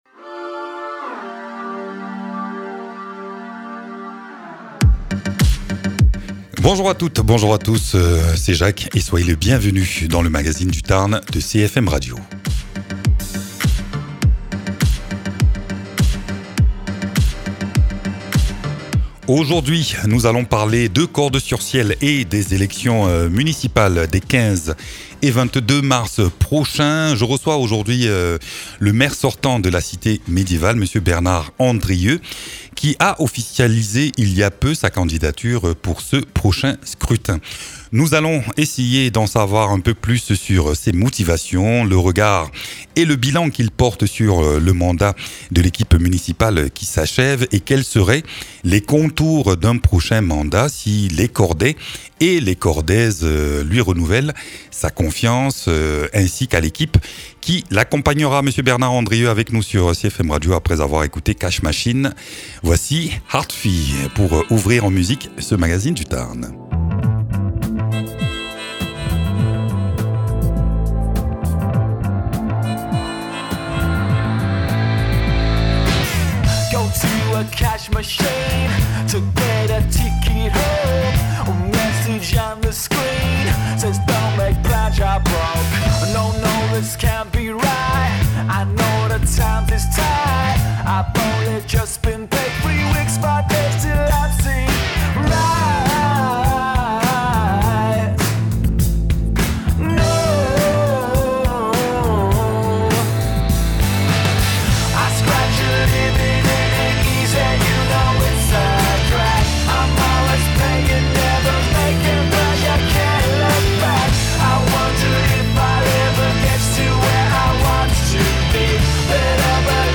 Invité(s) : Bernard Andrieu, maire de Cordes-sur-ciel.